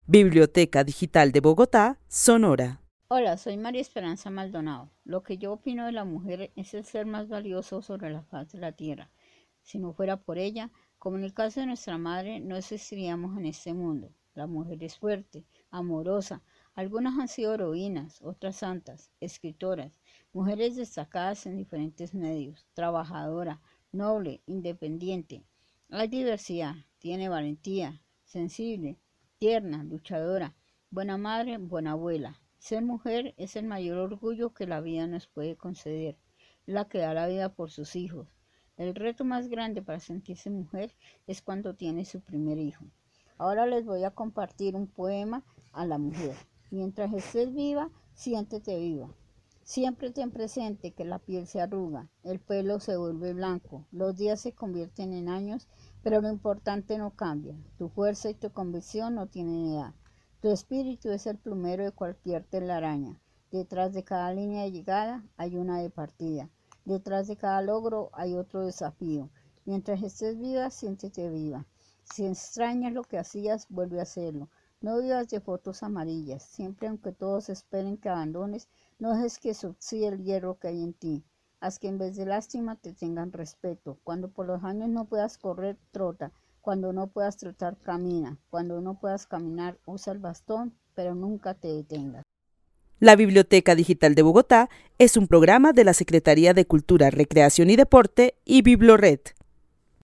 Narración oral sobre lo que significa ser mujer. La narradora es una mujer que reflexiona desde sus vivencias sobre ser mujer en Bogotá, exaltando cualidades que históricamente se han atribuido a las mujeres. Termina su relato con el poema "Mientras estés viva, siéntete viva", escrito por la Madre Teresa de Calcuta.